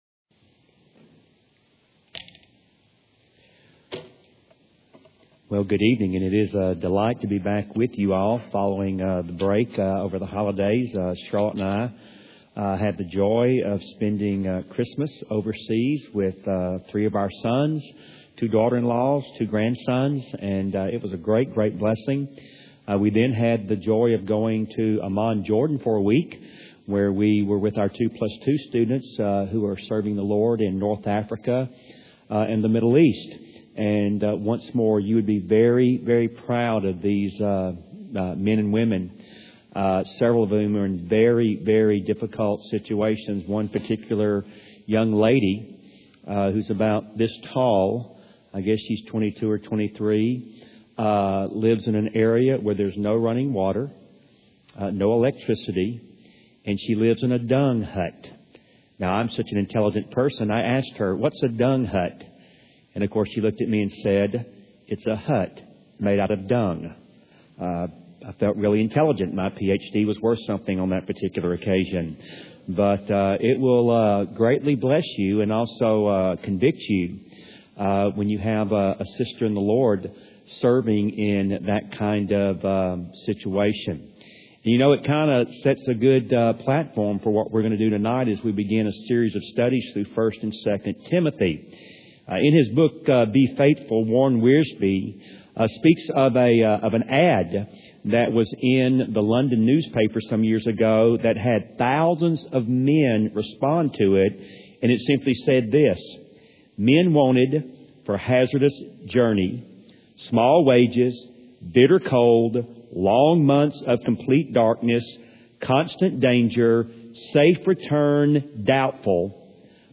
at Wake Cross Roads Baptist Church in Raleigh, NC